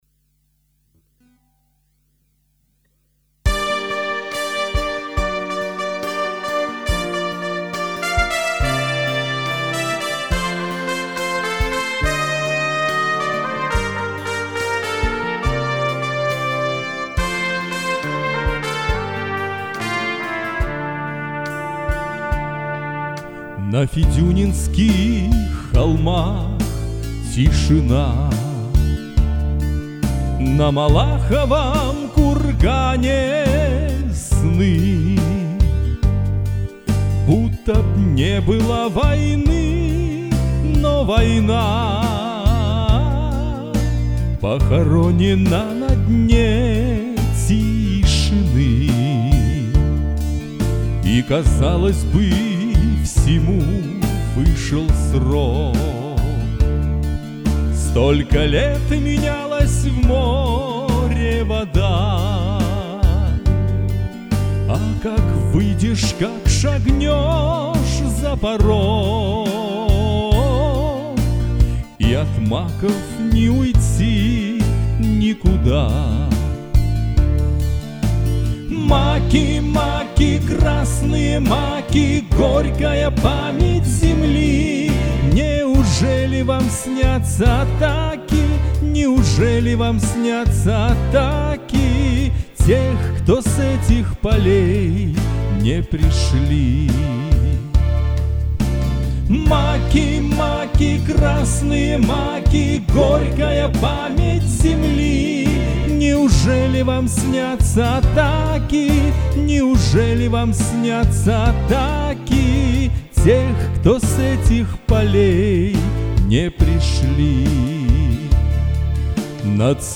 Оба исполнения мне понравились..